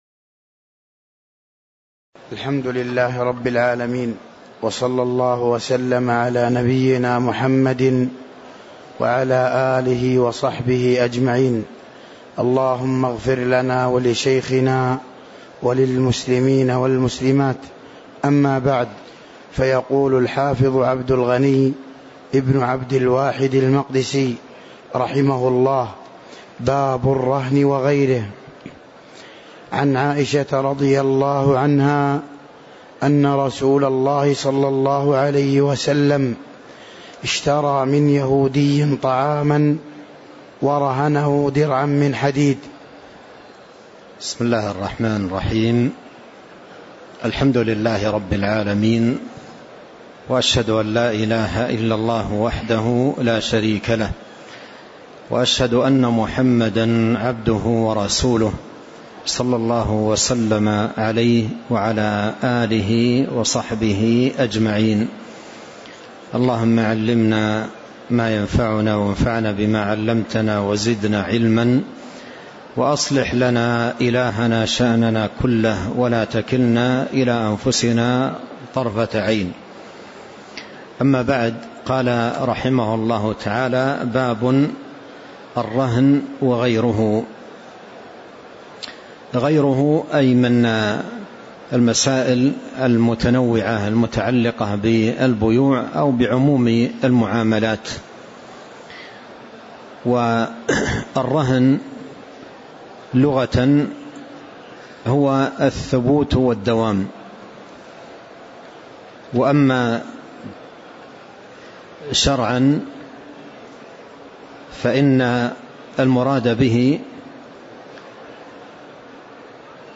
تاريخ النشر ٨ رجب ١٤٤٤ هـ المكان: المسجد النبوي الشيخ